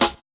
130UKSNAR3-L.wav